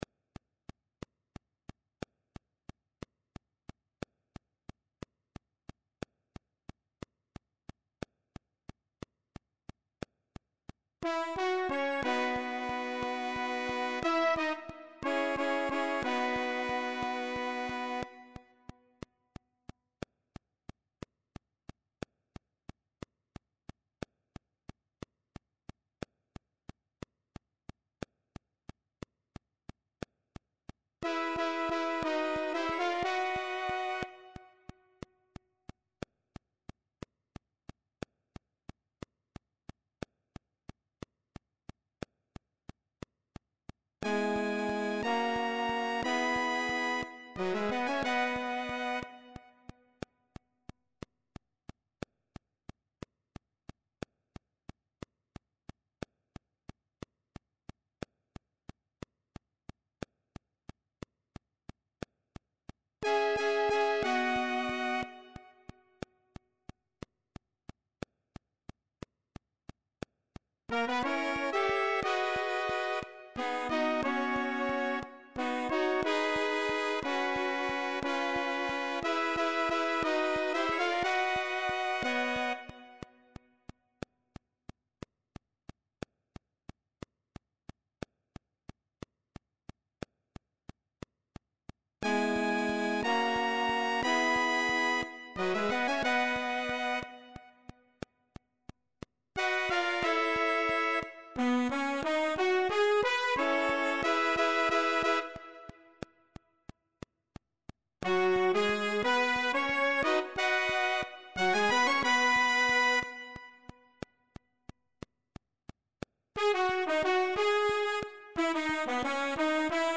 Partitura do Naipe de Metais para os seguintes instrumentos:
1. Sax Tenor;
2. Saxofone Alto;
3. Trompete; e,
4. Trombone.